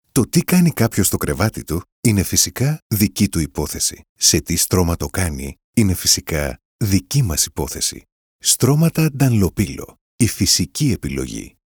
Commercieel, Natuurlijk, Vertrouwd, Warm, Zakelijk
Commercieel